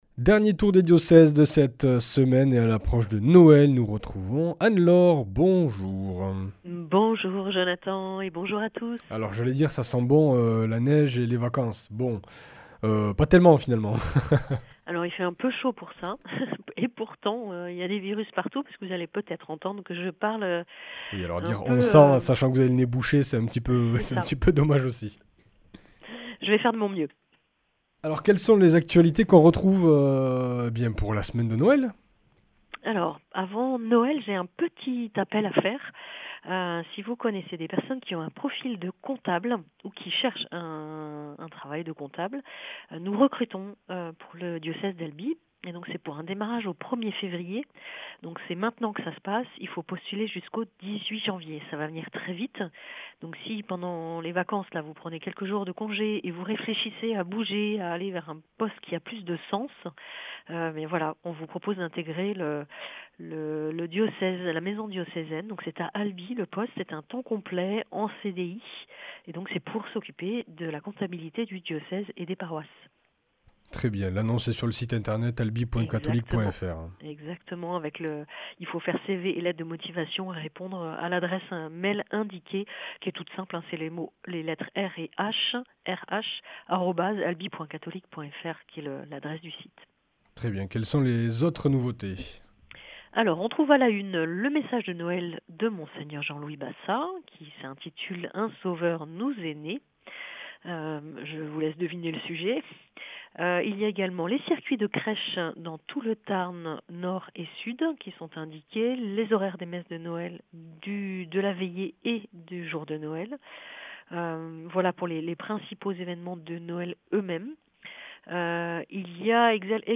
Message de Noël de Mgr Jean-Louis Balsa :